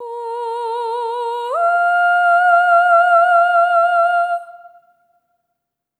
SOP5TH A#4-L.wav